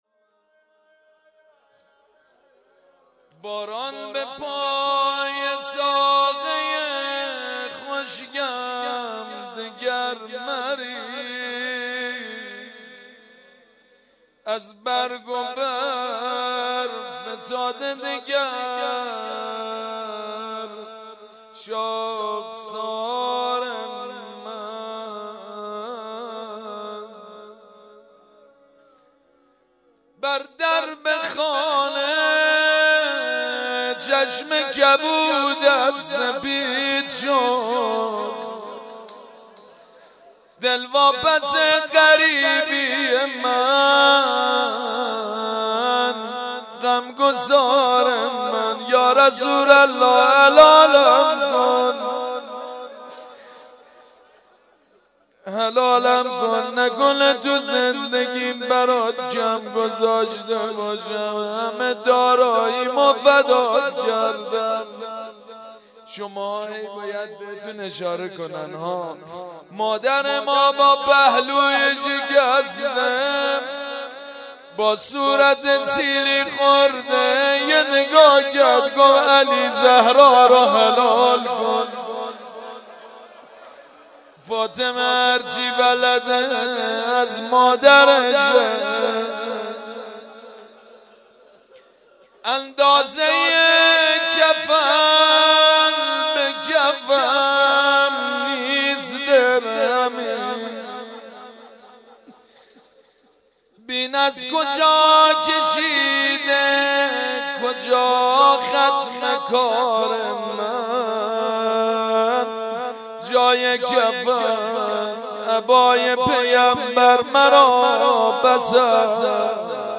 روضه
03.rozeh2.mp3